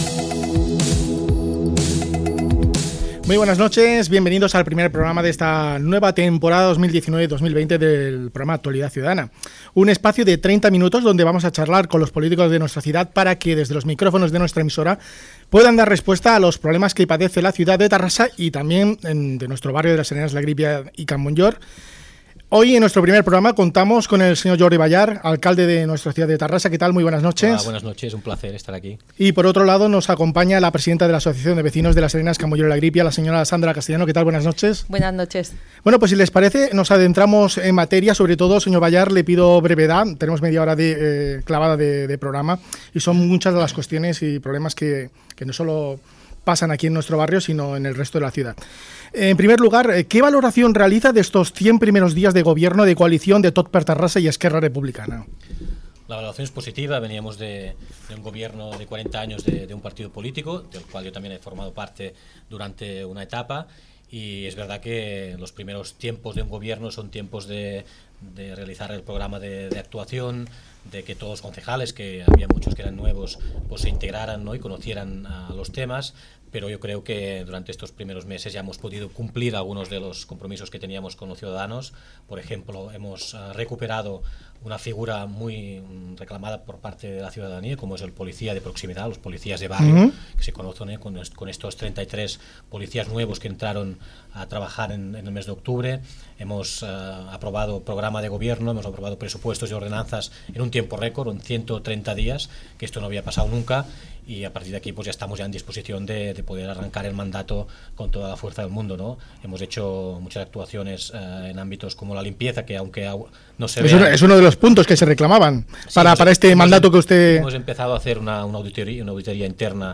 Entrevista a l'alcalde de Terrassa, Jordi Ballart
Gènere radiofònic Informatiu